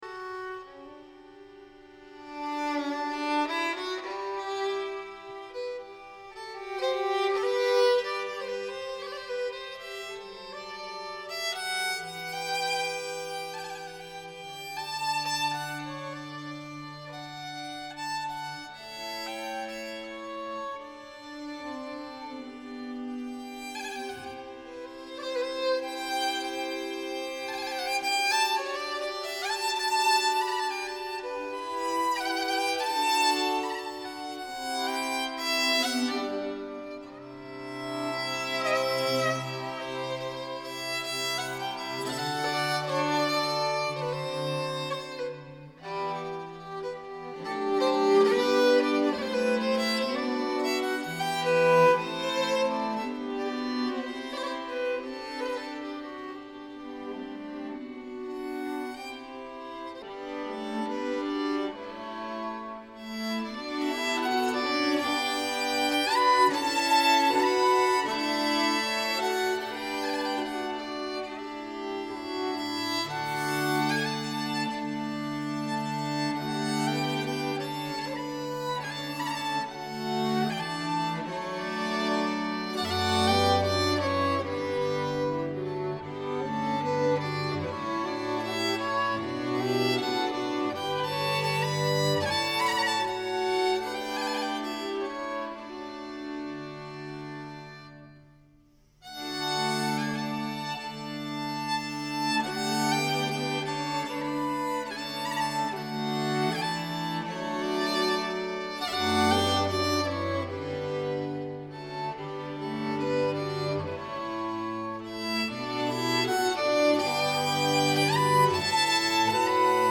Folk music
Style: Folk
altfiol
cello
Bearbetning för stråkkvintett
Inspelad i Gävle Konserthus 2018